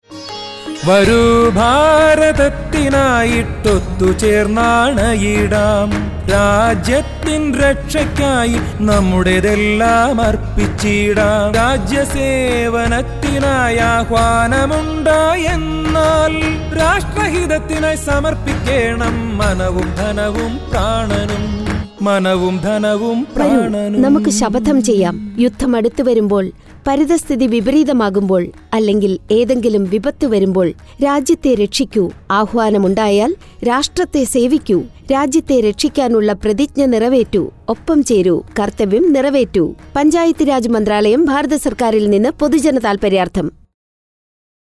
127 Fundamental Duty 4th Fundamental Duty Defend the country and render national services when called upon Radio Jingle Malayamlam